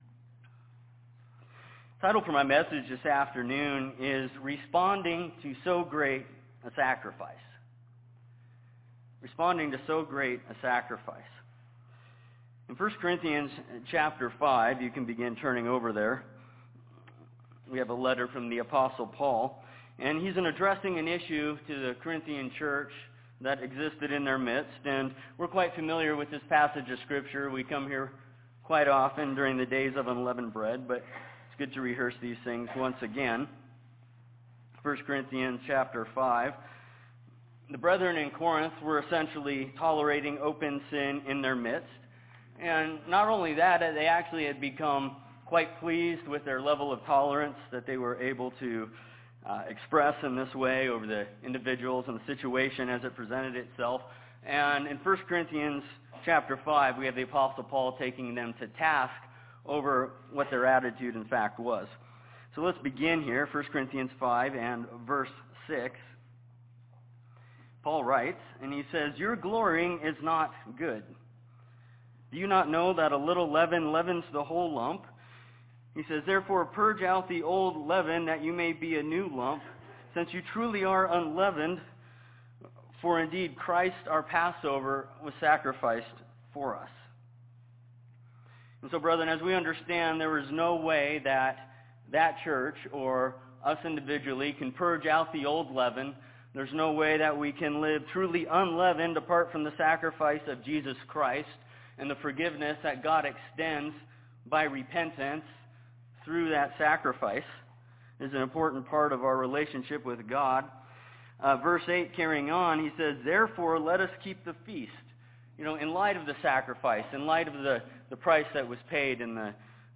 Sermon on afternoon of Last Day of Unleavened Bread, Friday, April 10, 2015 The Passover and Days of Unleavened Bread remind us of the price that was paid to bring us out of this world and into a reconciled relationship with God. In light of so great a sacrifice, our response must be to live lives of sacrifice before God and our fellow man.